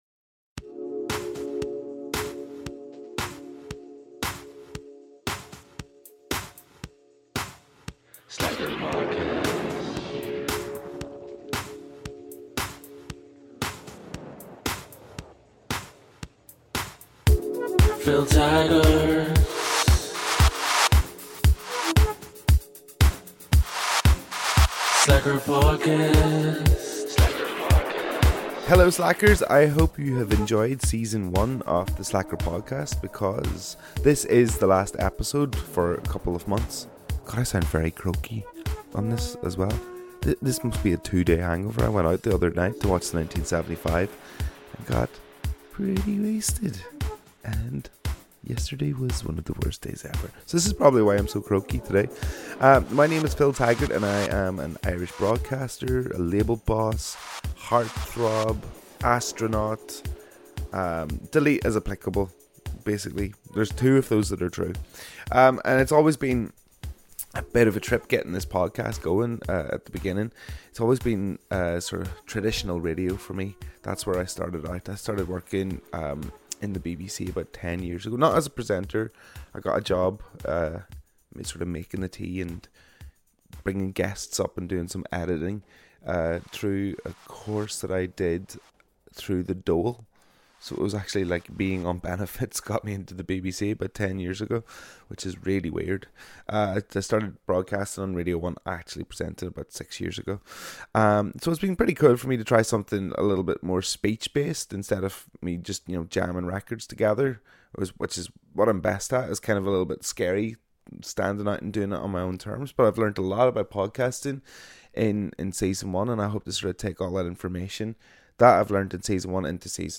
This week we welcome the Norwegian singer and songwriter Aurora to the podcast.